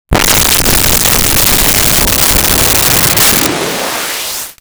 Alien Woosh 01
Alien Woosh 01.wav